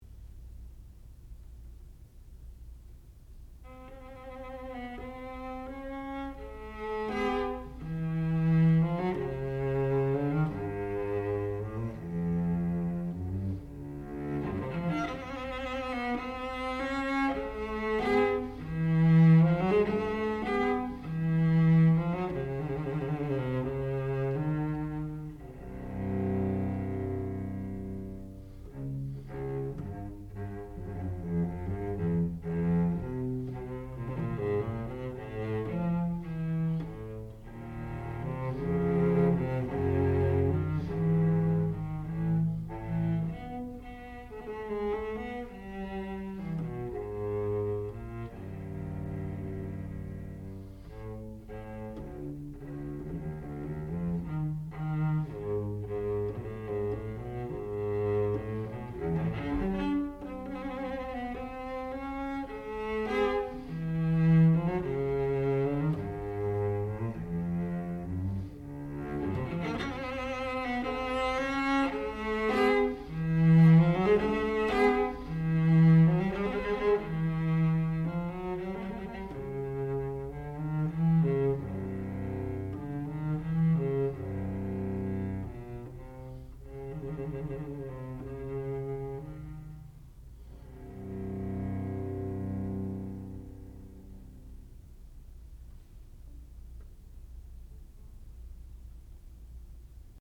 Sonata, op. 25, no. 3 for violoncello solo
classical music
Advanced recital